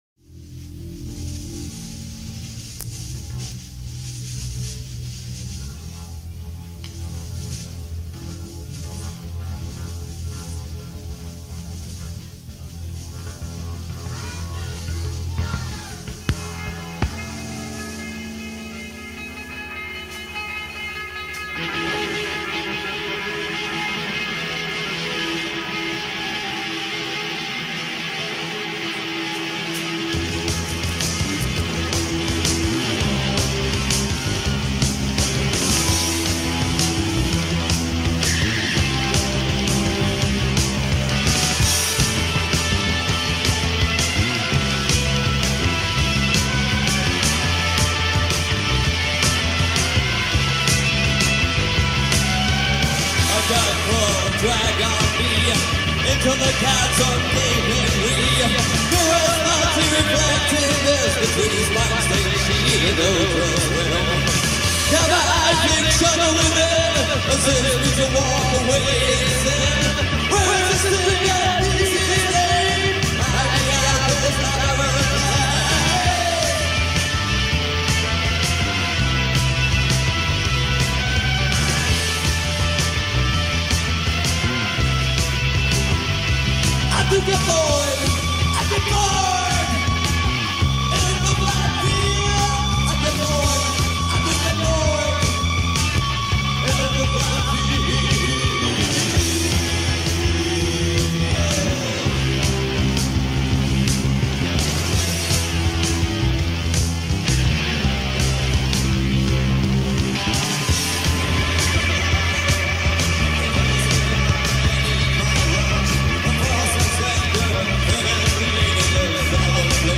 recorded live at Nijmgen, Netherlands on November 24, 1981
Intense, theatrical, post-punk and Goth.